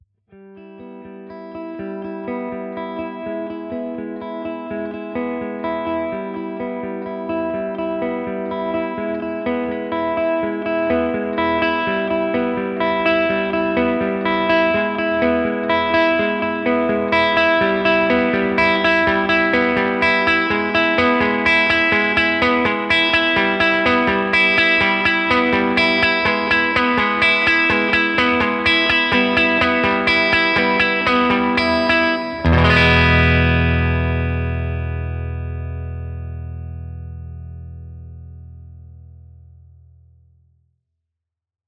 Fender®真空管アンプの公認コレクション
AmpliTube_Fender_2_Custom_Twin_Dynamic.mp3